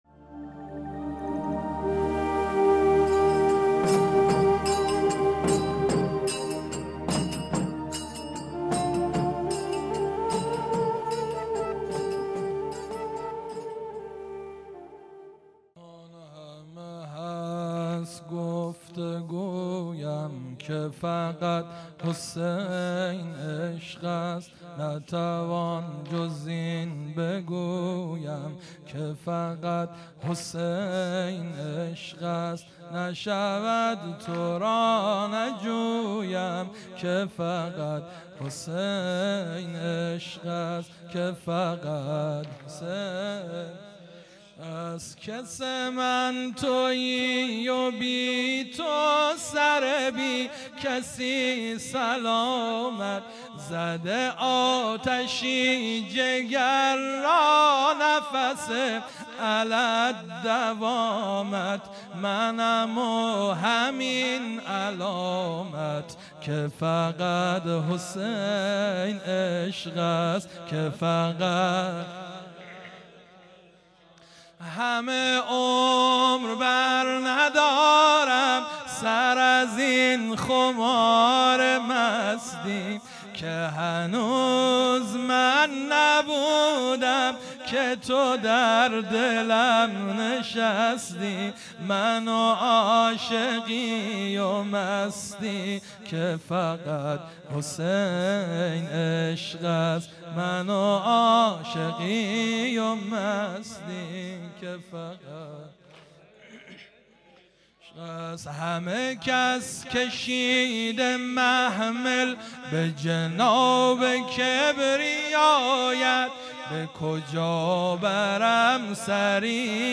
سنگین | همه هست گفت‌وگویم که فقط حسین عشق است
مداحی
در دهه‌ی اول محرم الحرام سال 1399 | هیأت انصار ولایت دارالعباده یزد